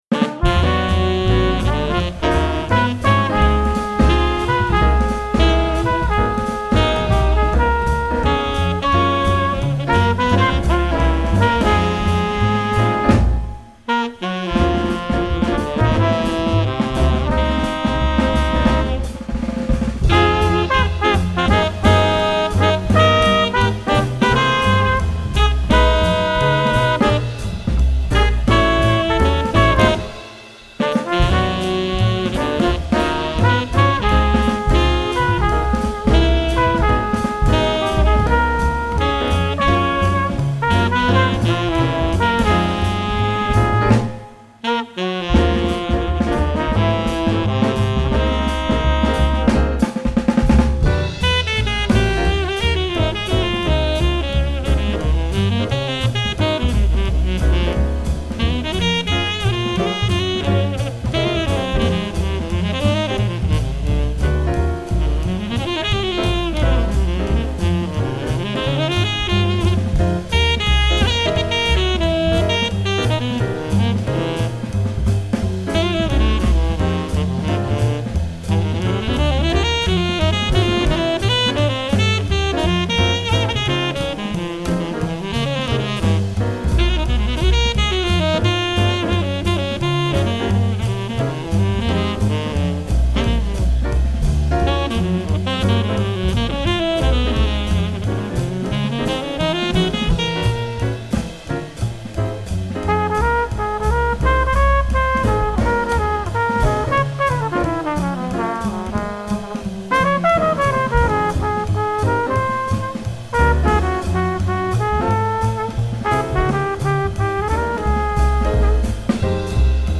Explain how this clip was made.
The musicians and sound quality are wonderful.